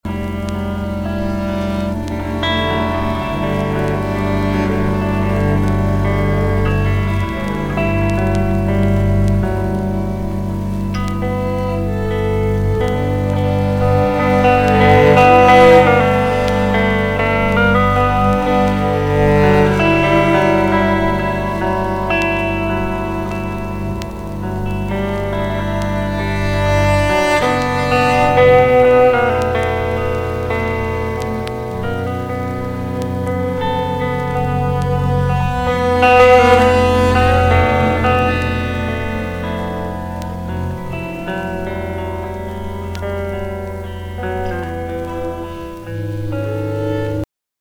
ALT./EXPERIMENTAL / INDIE / 90'S / GAREGE PUNK / INDIE POP